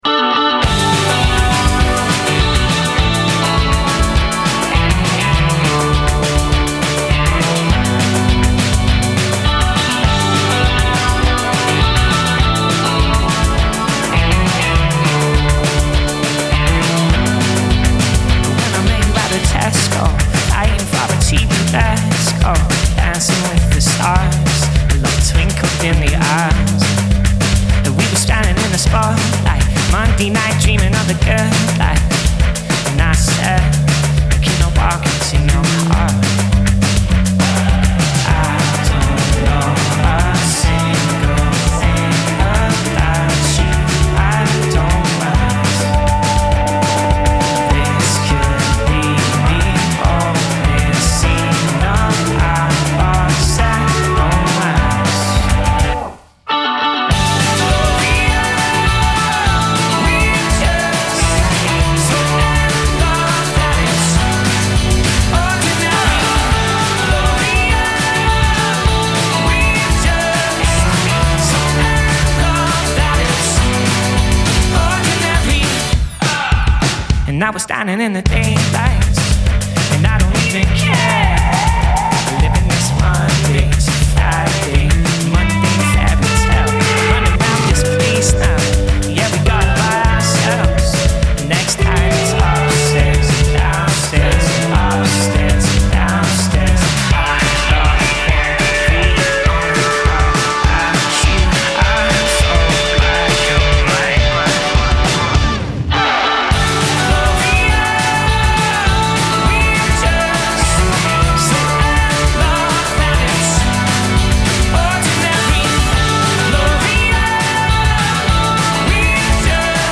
recorded in Glasgow
vocals and guitar
bass
drums